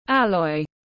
Hợp kim tiếng anh gọi là alloy, phiên âm tiếng anh đọc là /ˈælɔɪ/.
Alloy /ˈælɔɪ/